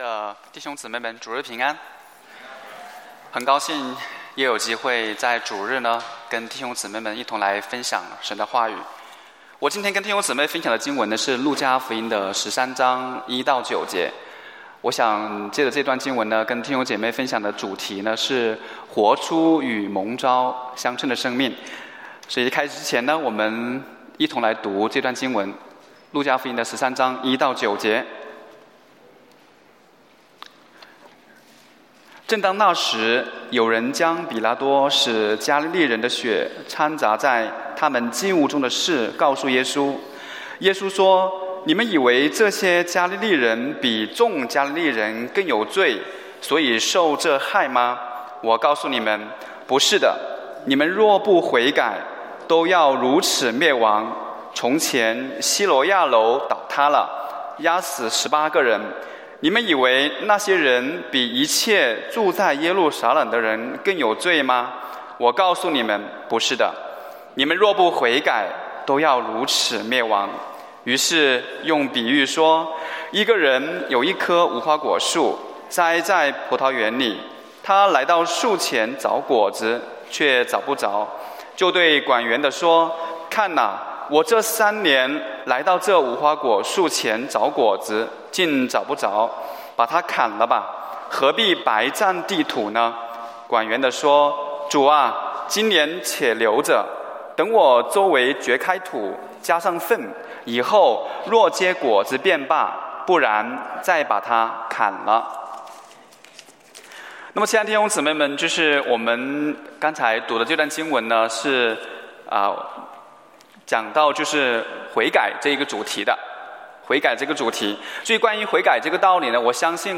在： Sermon